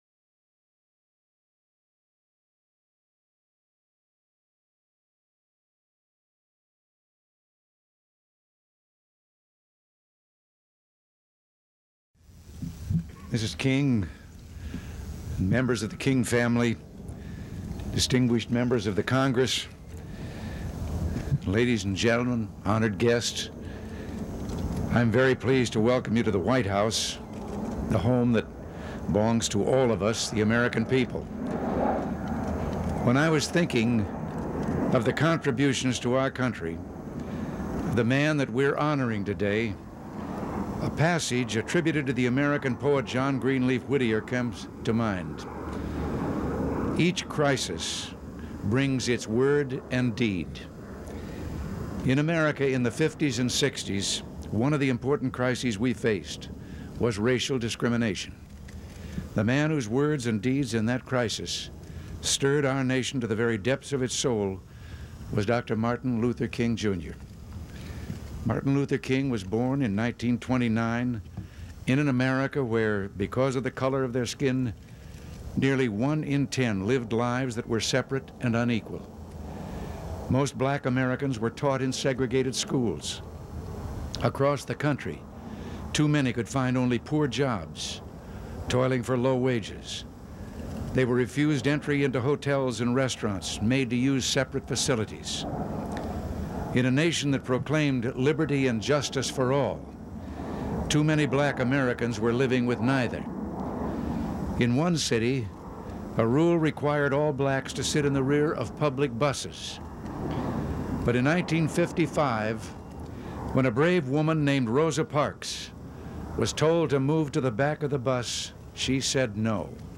Presidential Speeches
November 2, 1983: Speech on the Creation of the Martin Luther King, Jr., National Holiday